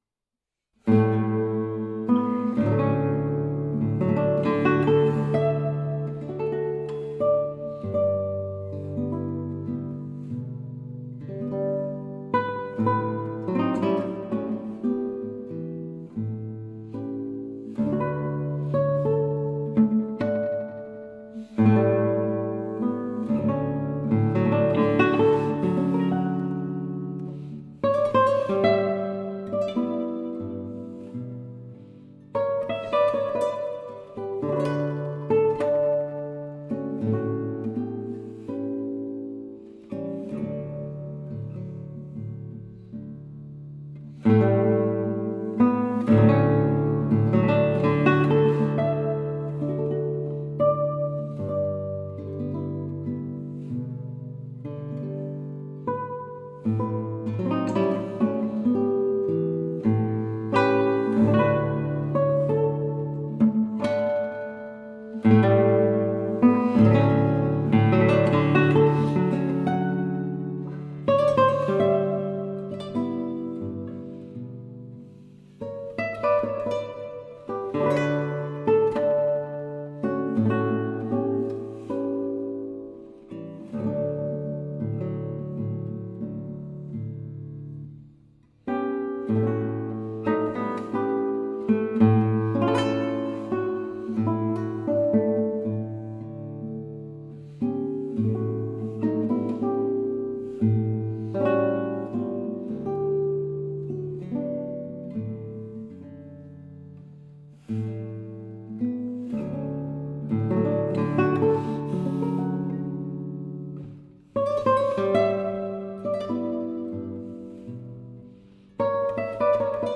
ギター編曲版